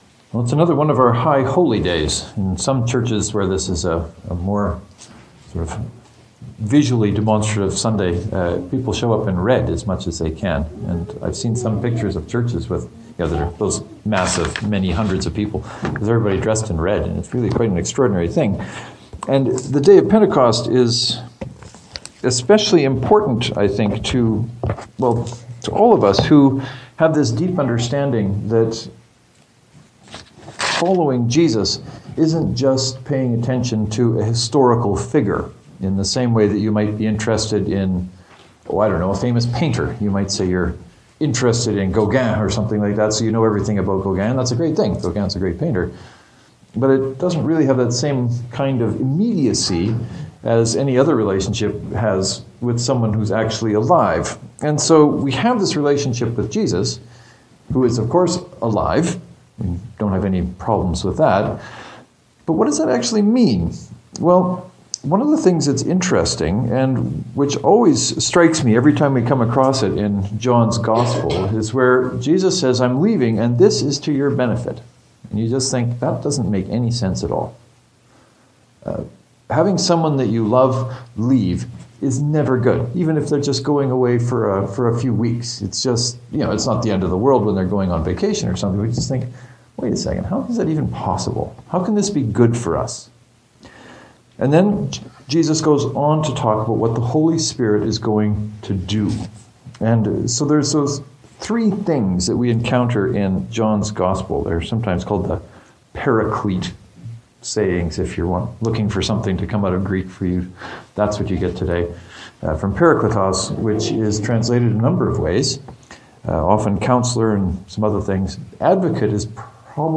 Jesus talks about the Advocate Testifying on Jesus’ behalf Proving the world wrong about its values Guiding us into all truth The two sermons below look at each of these Paraclete sayings and see what is there for us.